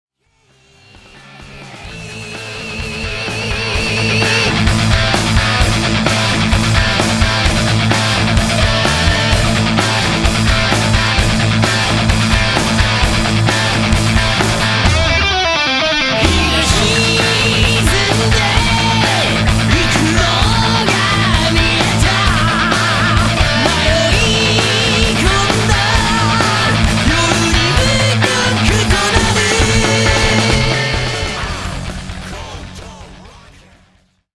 ハード・ロック・サウンドが身上だ。